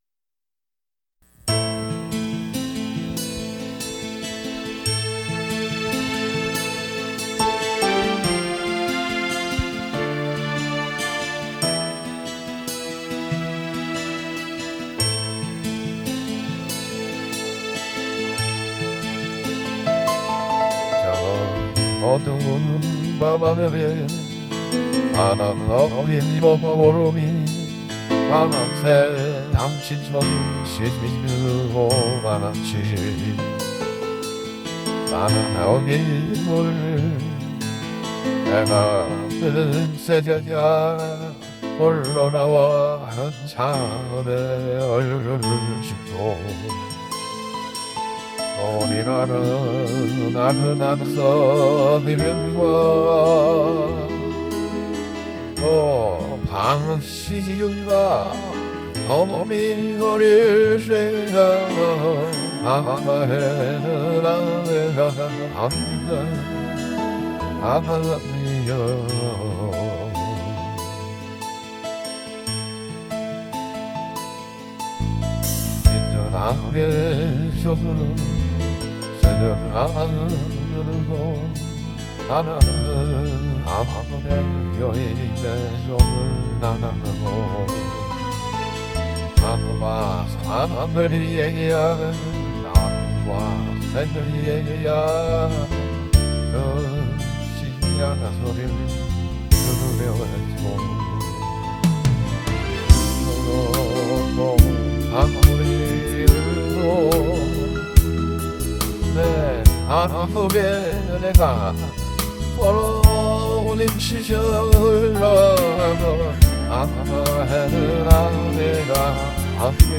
즉, 제가 불렀던 노래를 대상으로 제 ai voice를 적용하여 만들어진 저의 ai cover입니다.
2. 새롭게 만들어진 ai cover곡 :
반면 내가 엉성하게 불러댄 곡을 대상으로 한 생성물은 내 노래 솜씨가 그대로 반영되고 있습니다.
제가 부른 커버대상곡의 경우 그 생성곡은, 커버대상곡에 비해 목소리에 메가리가 없고 부족한 거 같습니다.